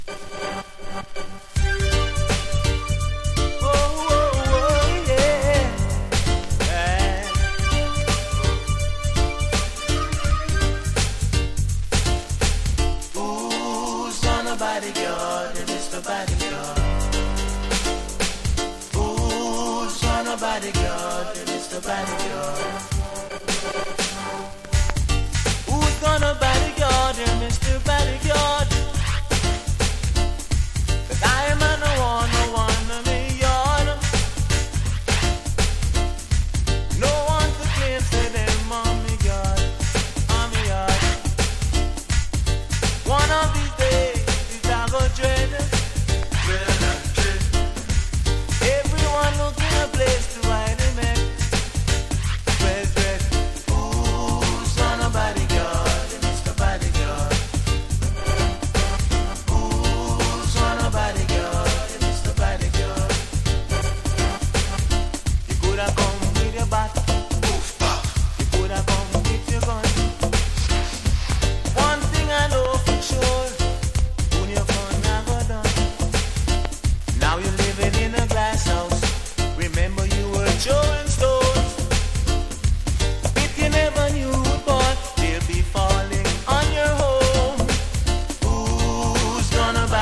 切れ味の鋭いダイナミックなリズムトラックと緊張感溢れるコーラス・ワークが最高です!!